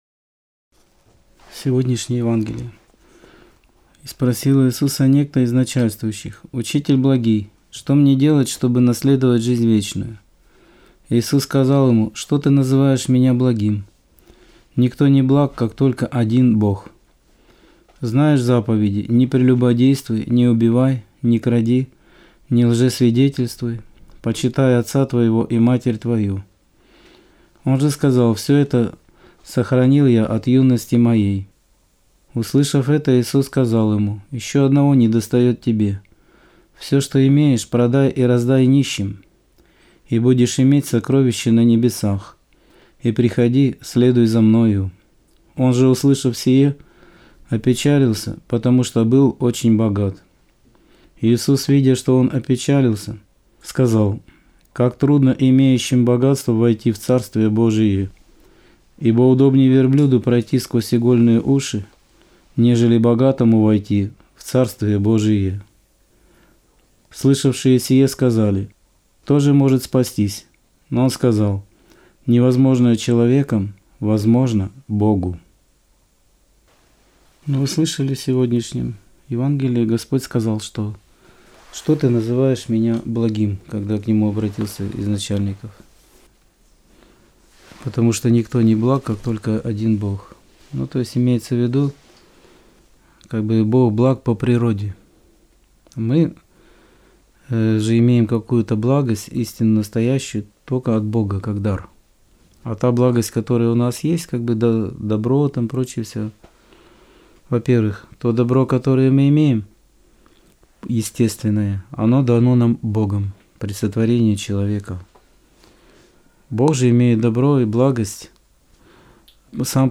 Аудио-проповедь 2.01.2022